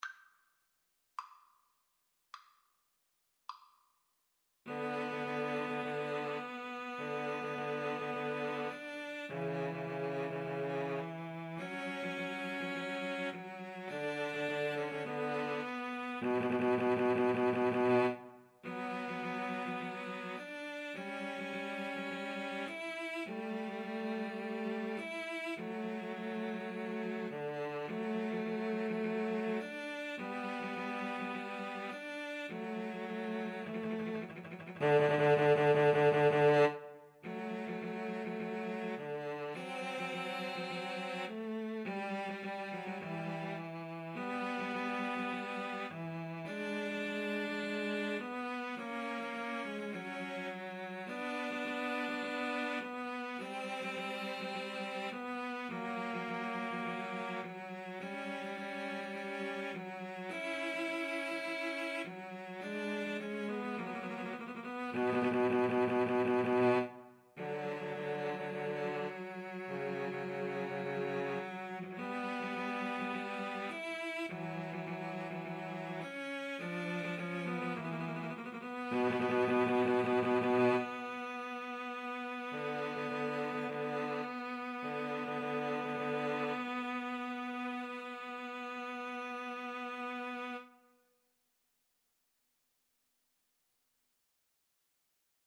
Free Sheet music for Cello Trio
B minor (Sounding Pitch) (View more B minor Music for Cello Trio )
Adagio = c. 52
Classical (View more Classical Cello Trio Music)